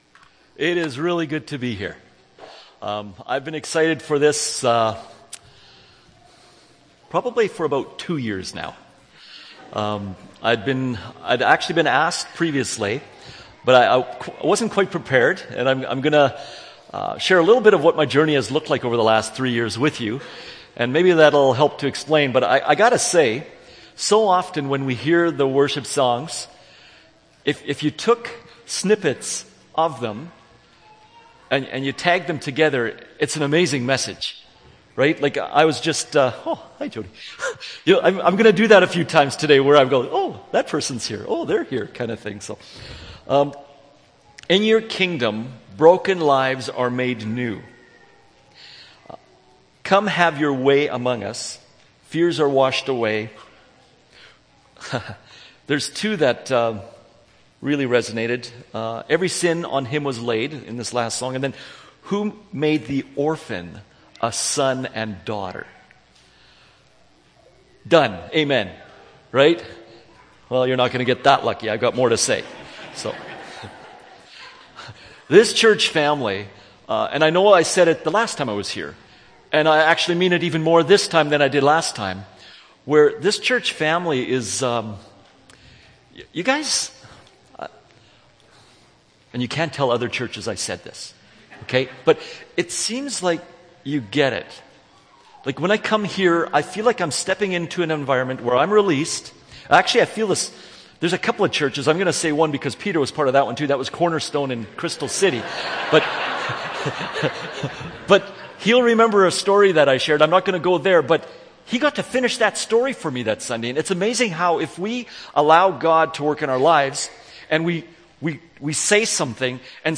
June 22, 2014 – Sermon